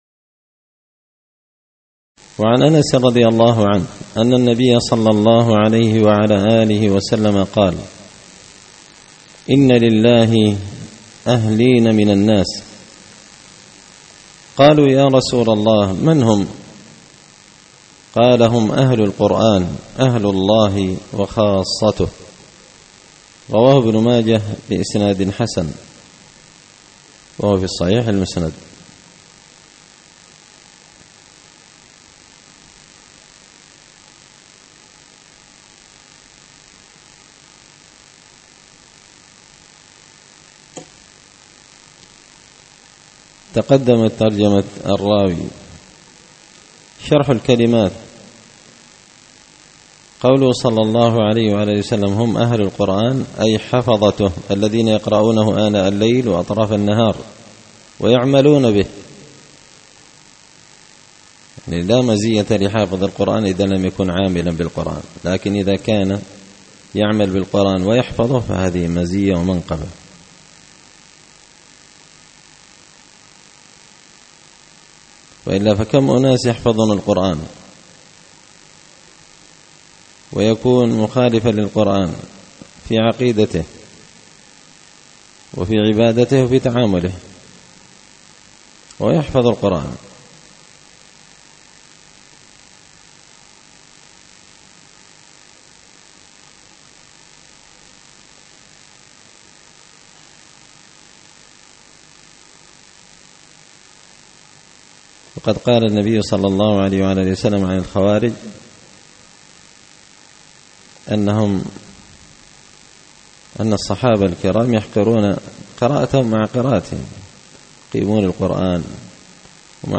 الأحاديث الحسان فيما صح من فضائل سور القرآن ـ الدرس الثالث والستون